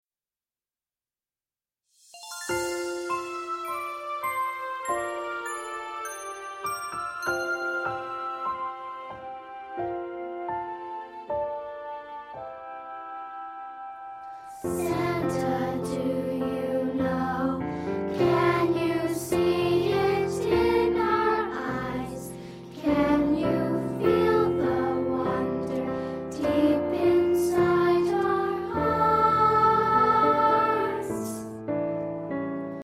▪ The full-length music track with vocals.